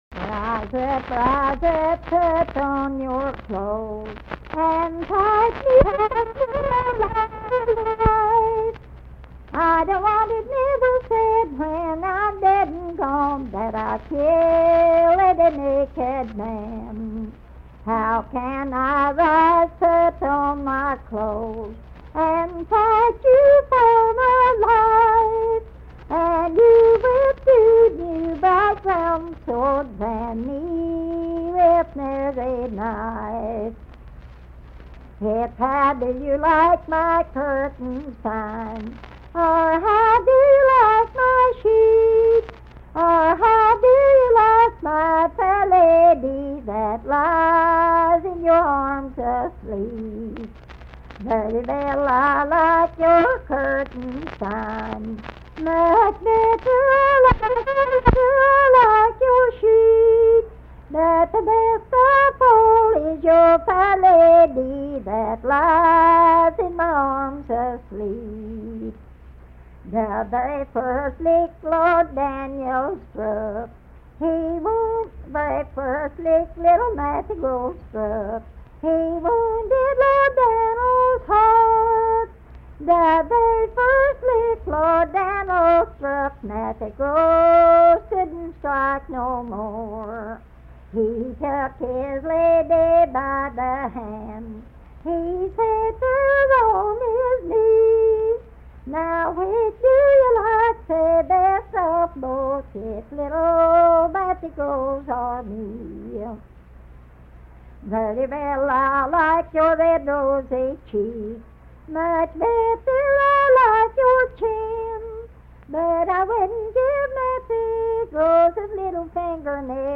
Unaccompanied vocal music
Verse-refrain 21(4).
Voice (sung)